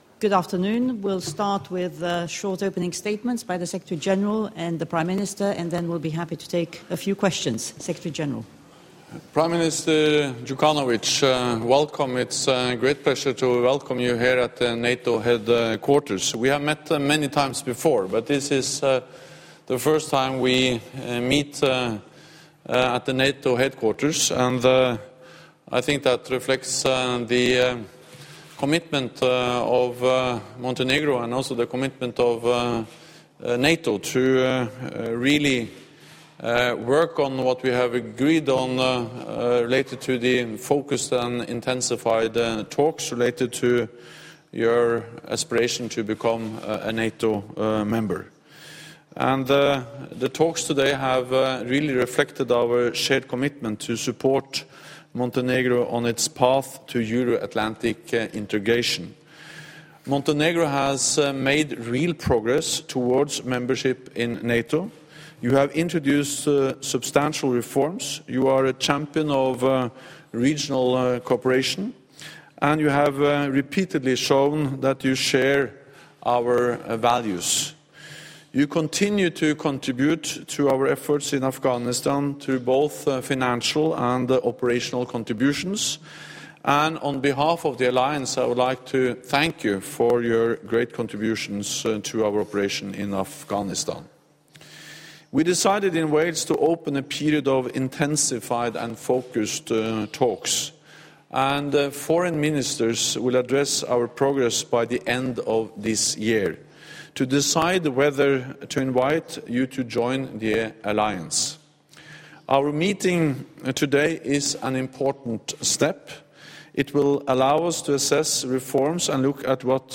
ORIGINAL - Joint press point by NATO Secretary General Jens Stoltenberg and Prime Minister of Montenegro Milo Đjukanović 15 Apr. 2015 | download mp3 ENGLISH - Joint press point by NATO Secretary General Jens Stoltenberg and Prime Minister of Montenegro Milo Đjukanović 15 Apr. 2015 | download mp3